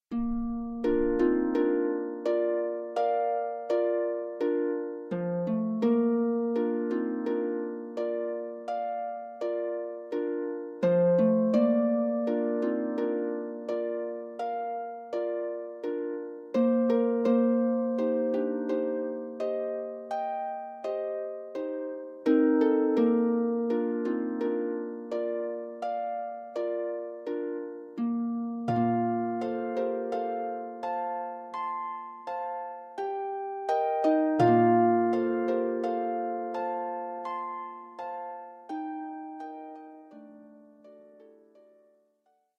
arranged for solo pedal harp.